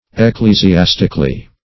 Ecclesiastically \Ec*cle`si*as"tic*al*ly\, adv.